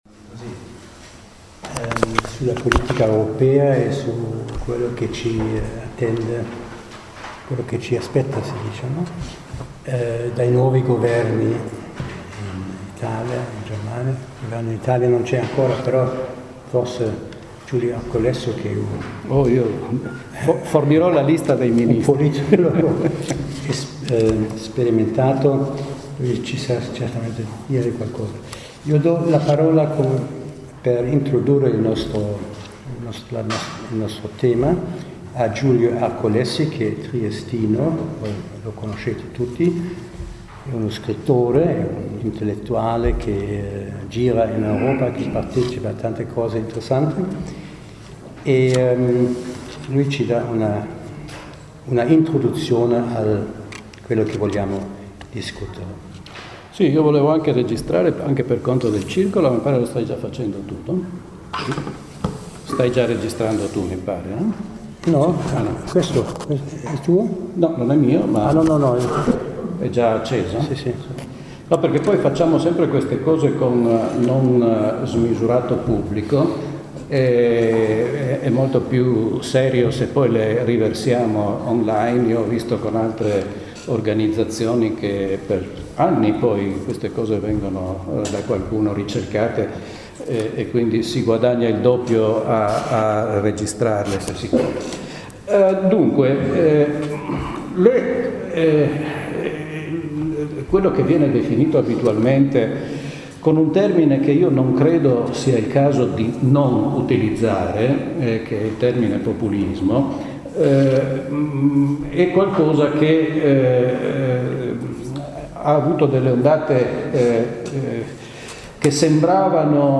Martedì 24 aprile, ore 17:00 Sala conferenze della Biblioteca Statale “S. Crise”, Largo Papa Giovanni XXIII n.6 Incontro con il dott.